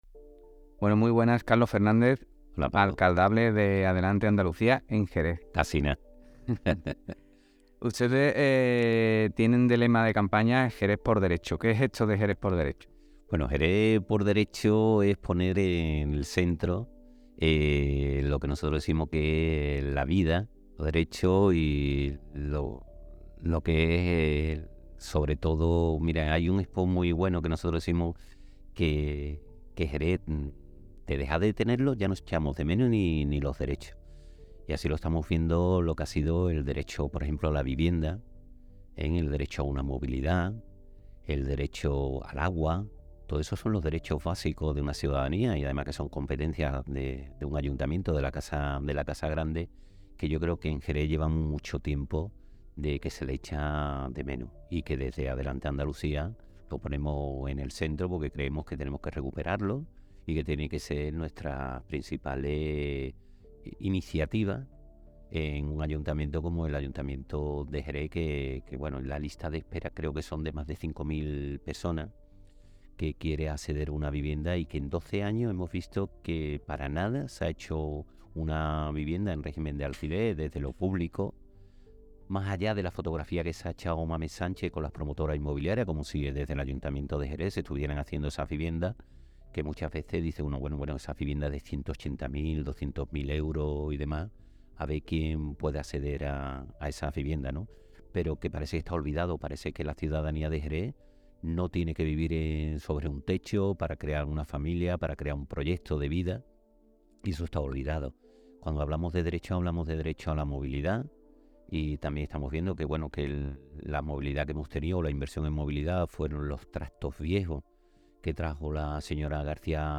En el podcast que acompaña esta pieza puedes escuchar la entrevista íntegramente.